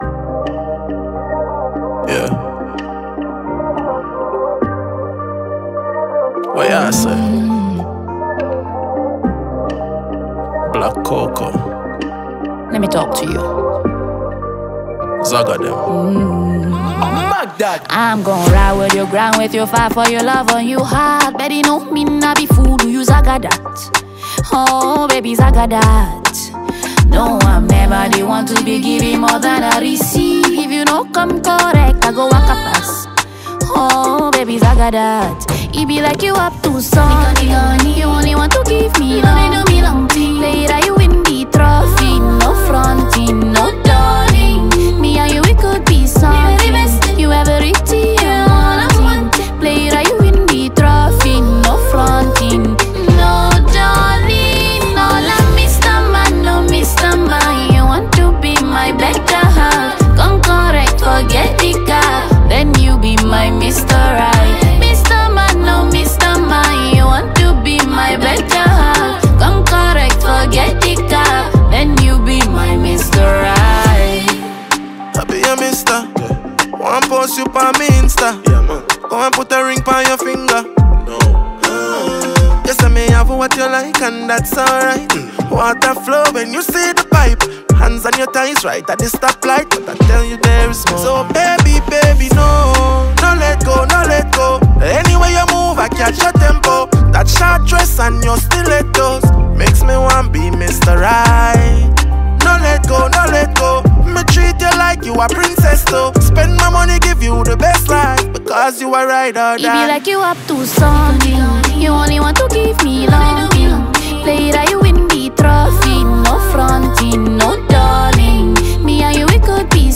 rap
energetic new anthem